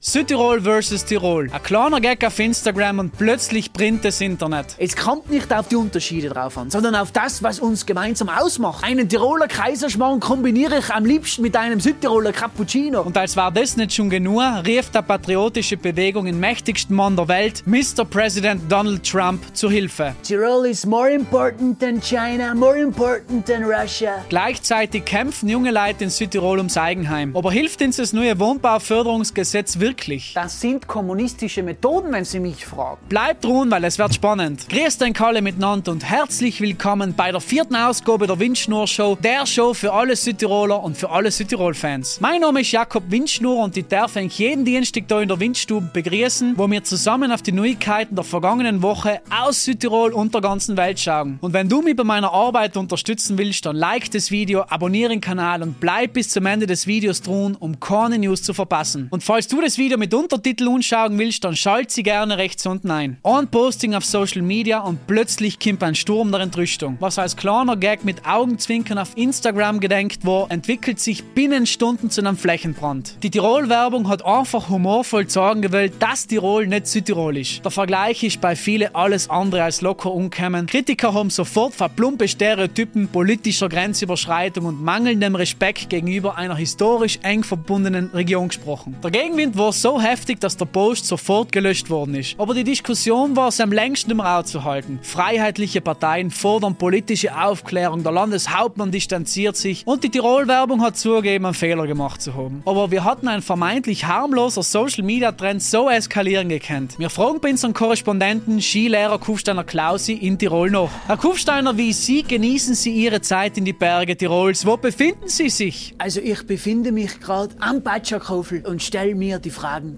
Woche humorvoll, pointiert und mit Dialekt auf das aktuelle